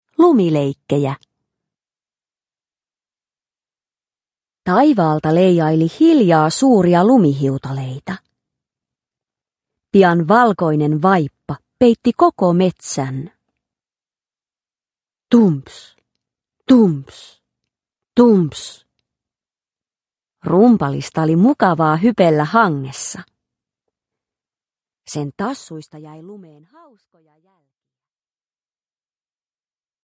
Lumileikkejä (ljudbok) av Disney